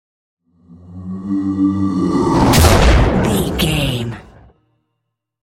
Creature whoosh to hit large
Sound Effects
Atonal
scary
ominous
eerie
woosh to hit